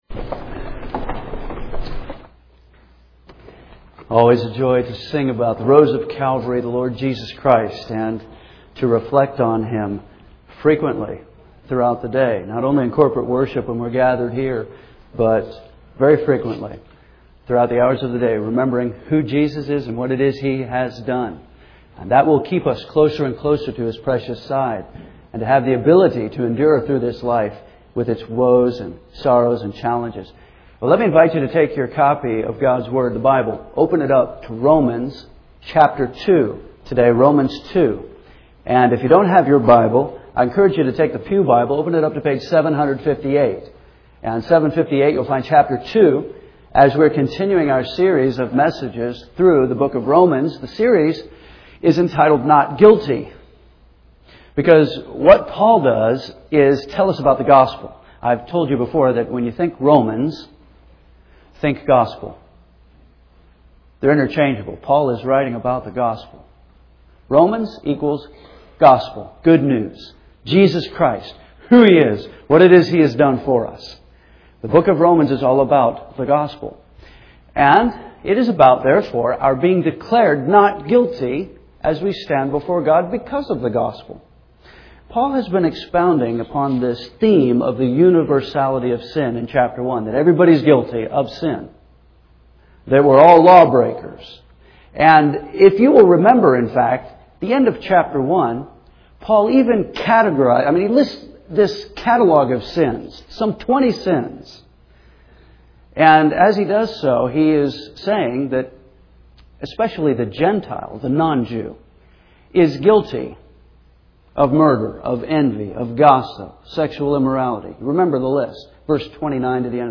First Baptist Church Henderson, KY